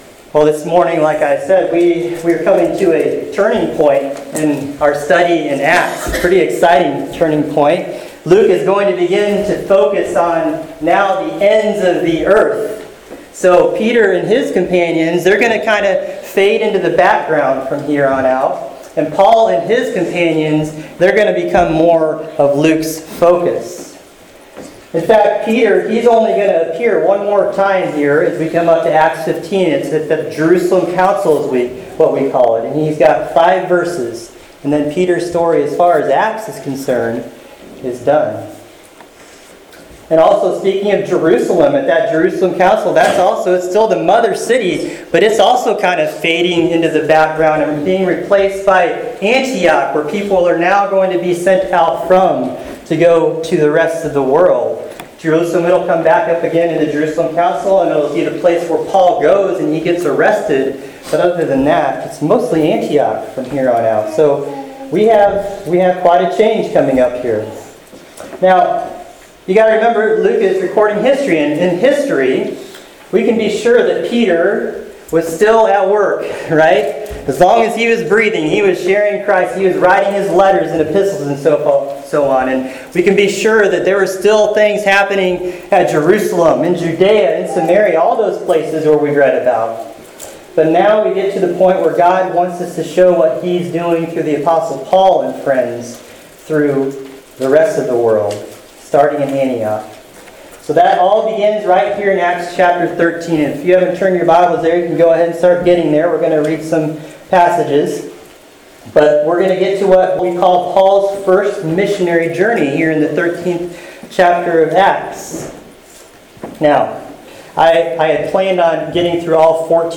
Acts 13:1-14 – The Spirit Sends, Satan Opposes, part 1 – First Baptist Church of Clear Lake, SD